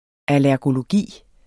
Udtale [ alæɐ̯goloˈgiˀ ]